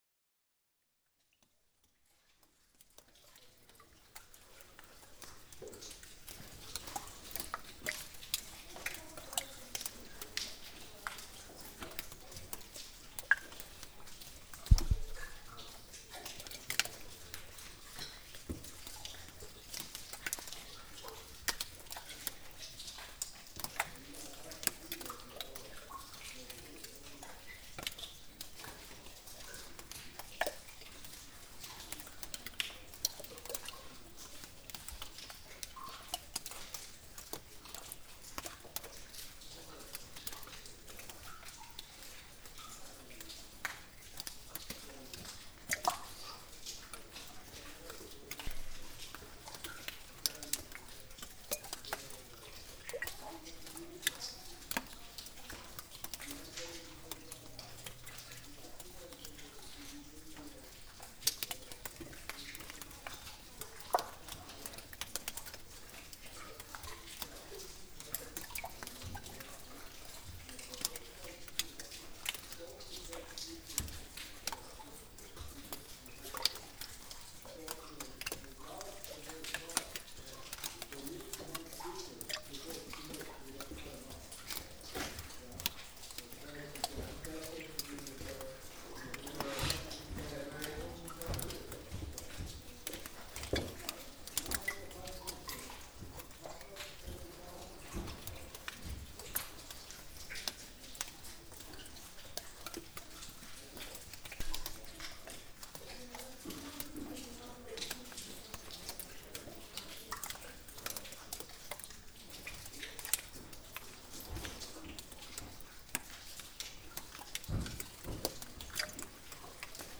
The sound in Raufarhólshellir
Early May I went into the Raufarhólshellir cave with a group of peoples. The tour was done to listen to the sound in the cave when water drops fall on ice on the floor. This sound can be really amazing when hundreds of drops fall in to all kinds of sizes and depths of holes in the ice. But, most of the ice was gone, so the sound was not as expected.
Posted in Náttúra, tagged Cave, Hellir, ORTF, Raufarhólshellir, Sennheiser MKH8040, Sound Devices 744 on 17.8.2013| 2 Comments »